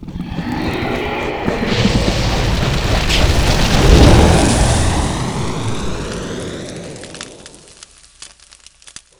firebolt.wav